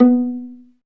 Index of /90_sSampleCDs/Roland L-CD702/VOL-1/STR_Viola Solo/STR_Vla Pizz